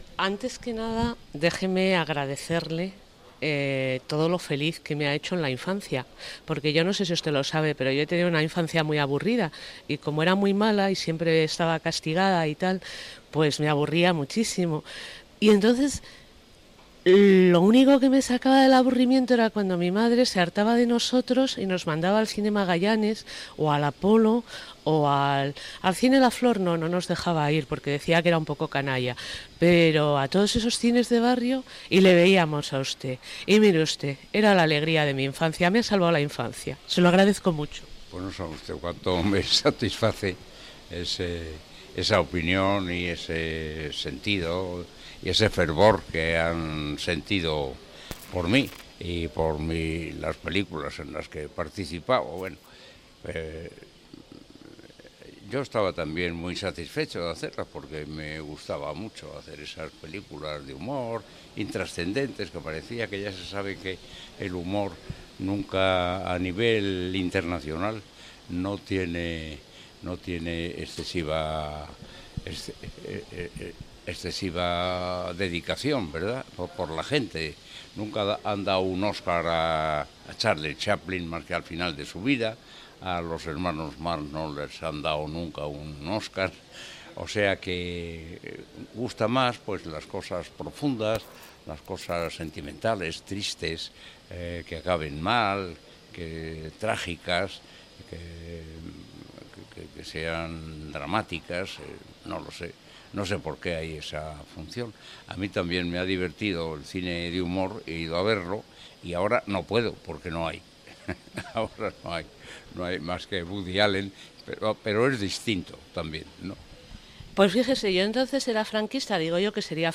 Entrevista a l'actor José Luis López Vázquez sobre la tipologia de personatge que ha interpretat